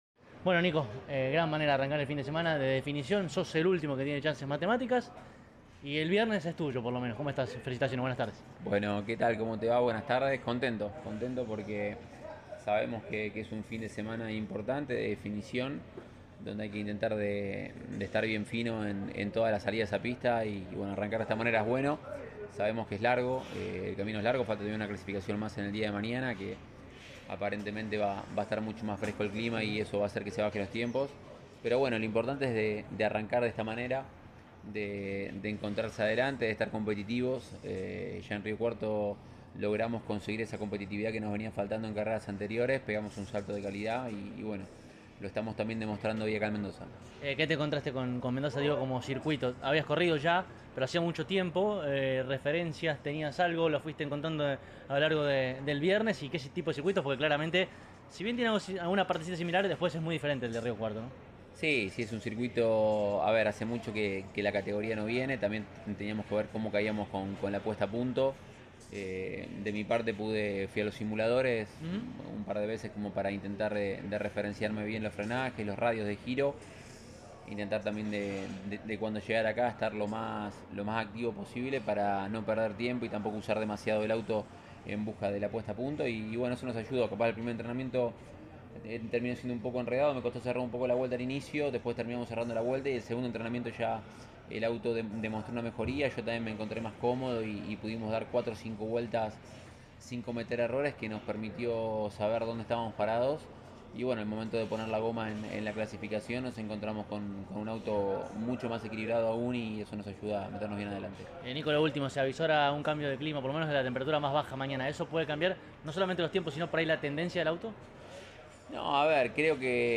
en diálogo con CÓRDOBA COMPETICIÓN: